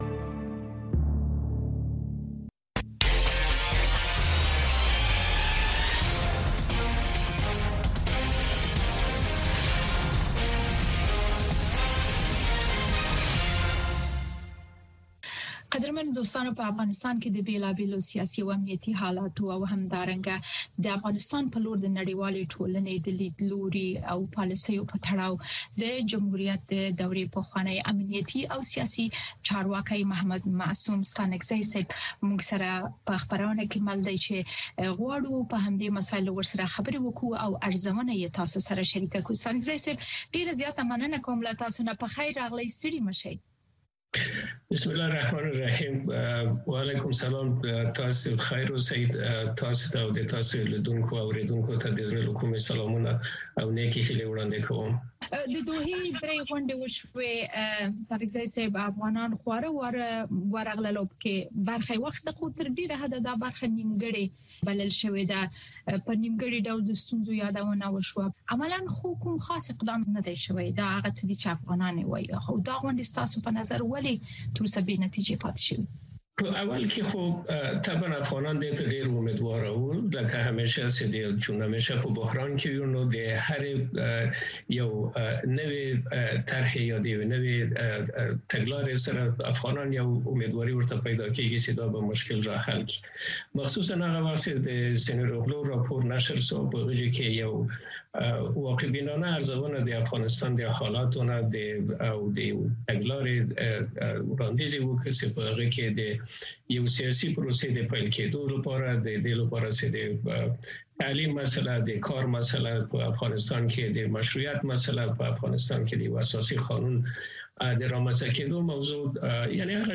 د افغانستان او نړۍ د تودو موضوعګانو په هکله د مسولینو، مقاماتو، کارپوهانو او څیړونکو سره ځانګړې مرکې هره چهارشنبه د ماښام ۶:۰۰ بجو څخه تر ۶:۳۰ بجو دقیقو پورې د امریکاغږ په سپوږمکۍ او ډیجیټلي خپرونو کې وګورئ او واورئ.